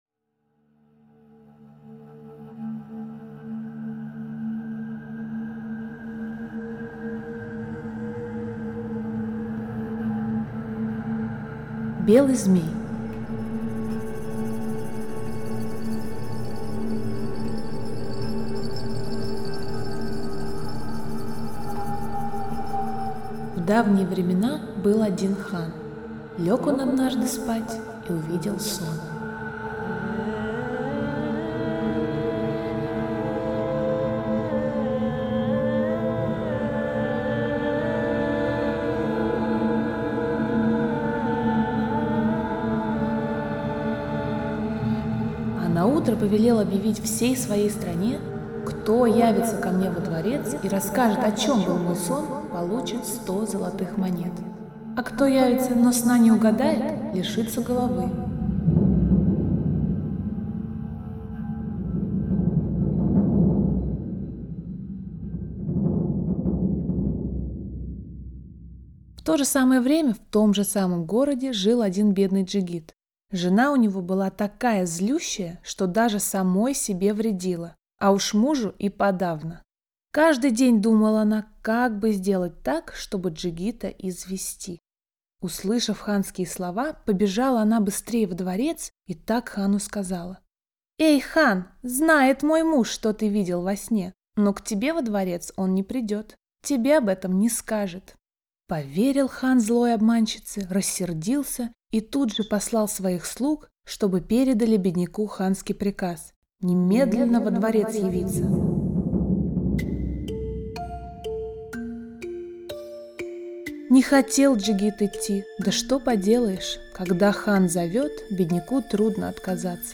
Белый змей - татарская аудиосказка - слушать онлайн